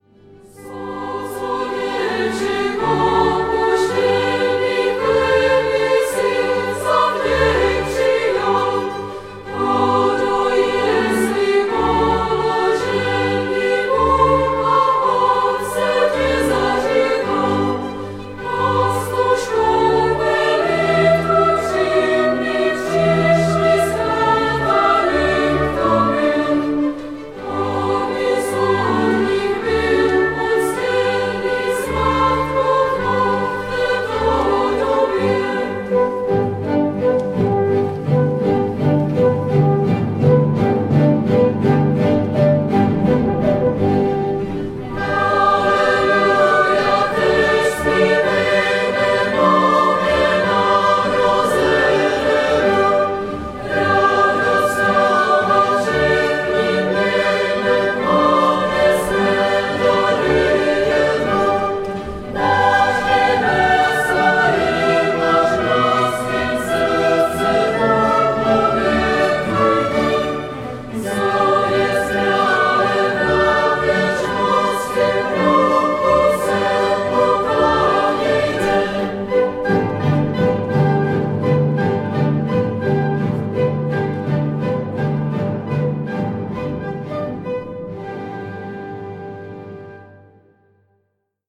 zpěv